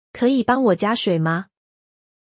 Kěyǐ bāng wǒ jiā shuǐ ma?